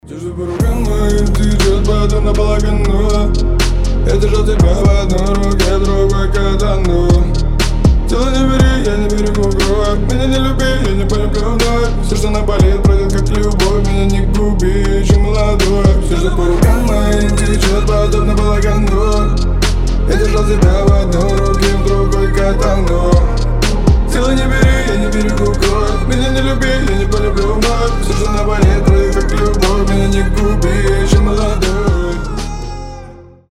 • Качество: 320, Stereo
мужской голос
мрачные